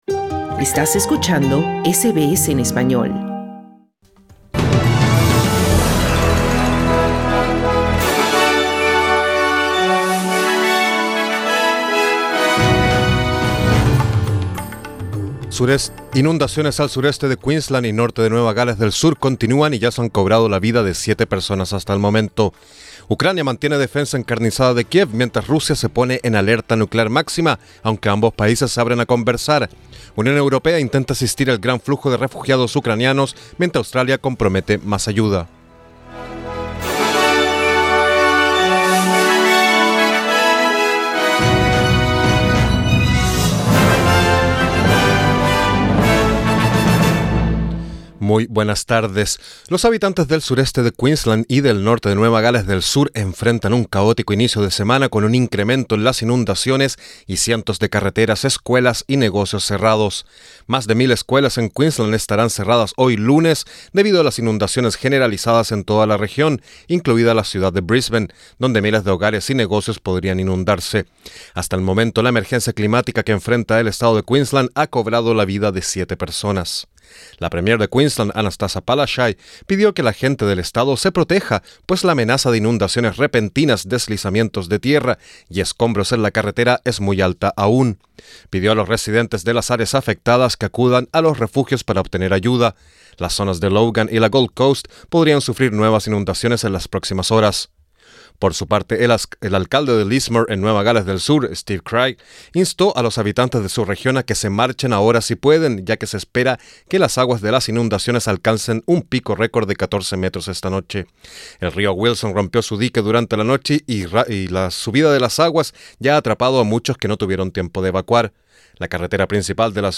Noticias SBS Spanish | 28 febrero 2022